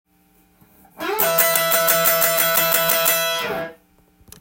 Shotgun Bluesで弾いているフレーズを耳コピしてみました。
譜面通り弾いてみました
Emペンタトニックスケールが主になります。
音符は連打する時はダウンピッキング。
エレキギターでカンタンに弾けるブルース